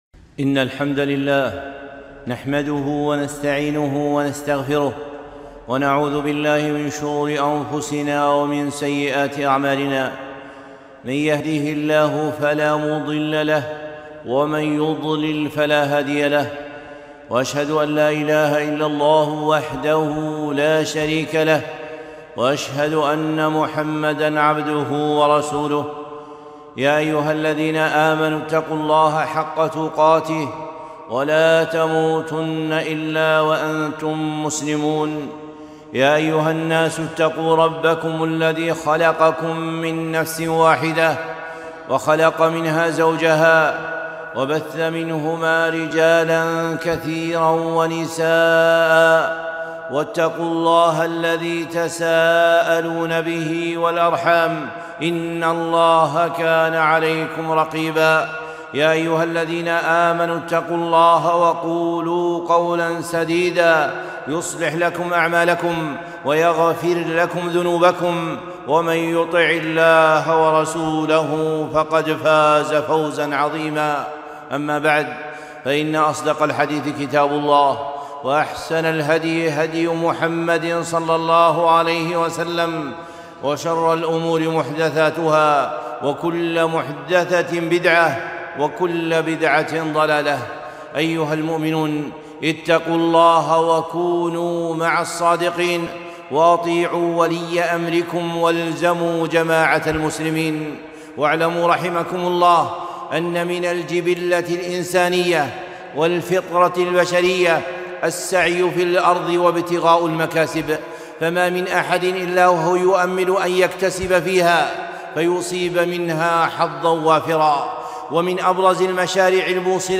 خطبة - التجارة مع الله